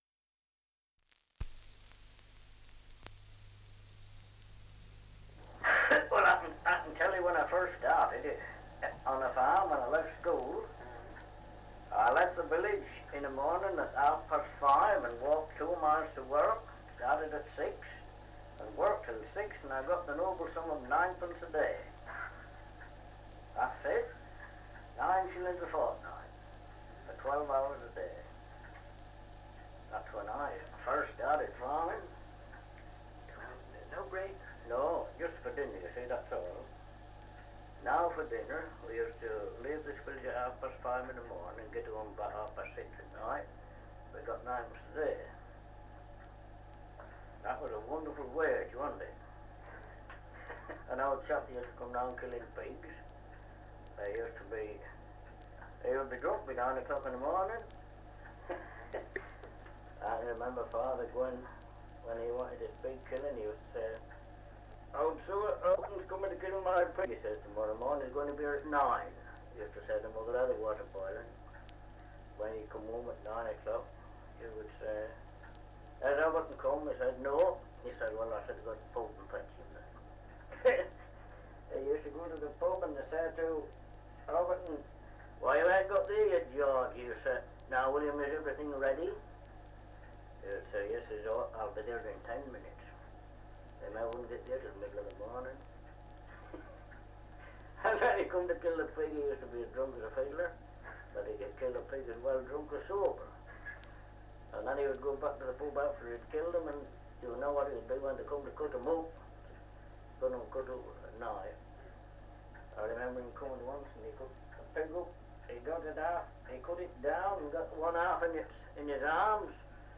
Survey of English Dialects recording in Keelby, Lincolnshire
78 r.p.m., cellulose nitrate on aluminium